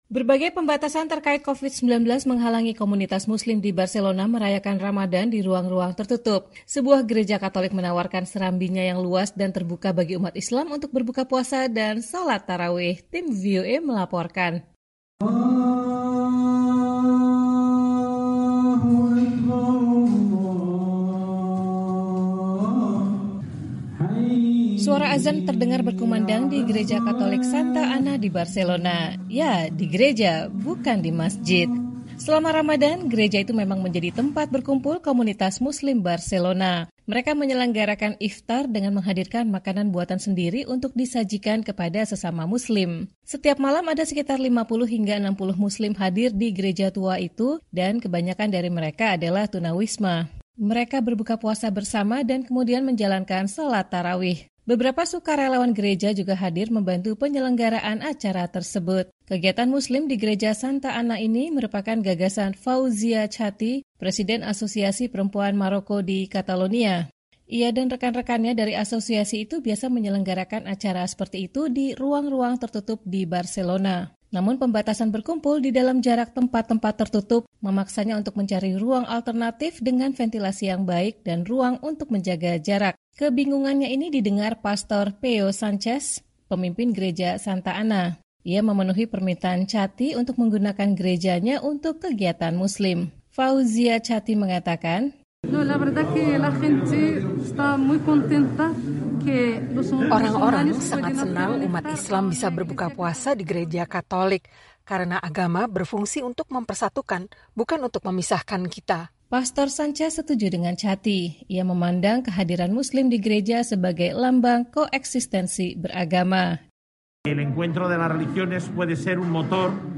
Suara azan terdengar berkumandang di Gereja Katolik Santa Anna di Barcelona.